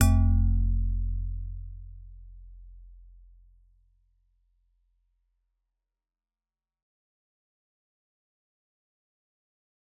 G_Musicbox-F1-f.wav